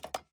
Clocks, Misc, Old Metallic Alarm Clocks, Handling 01 SND102354.wav